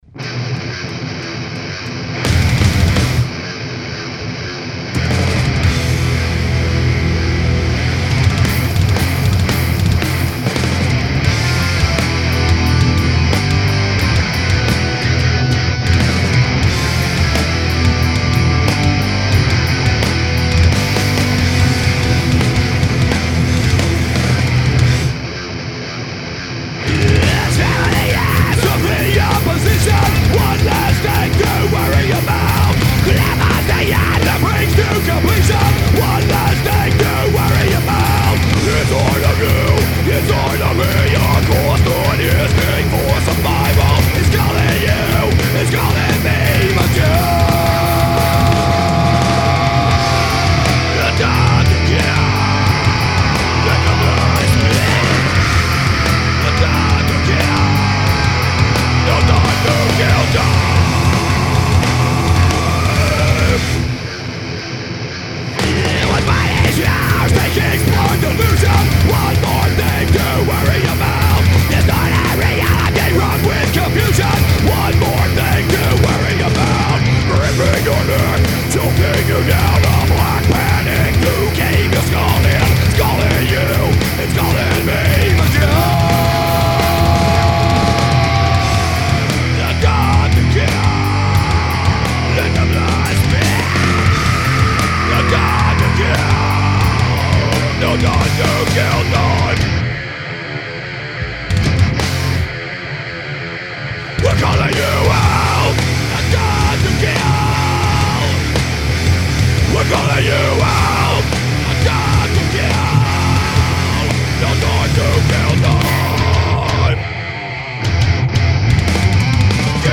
Heavy metal
Thrash/hardcore